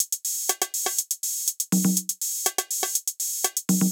AIR Beat - Perc Mix 2.wav